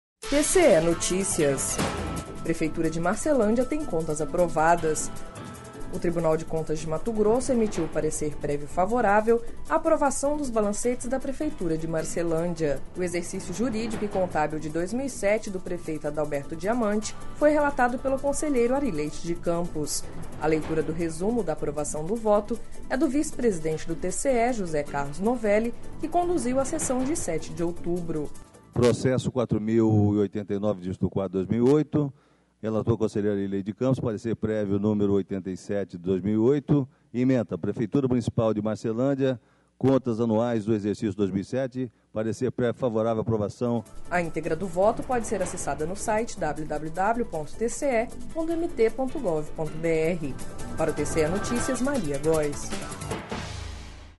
A leitura do resumo da aprovação do voto é do vice-presidente do TCE-MT, José Carlos Novelli, que conduziu a sessão de 07 de outubro.